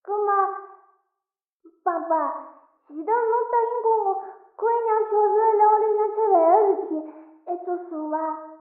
c03_三楼窗户人影偷听_小小蝶16.ogg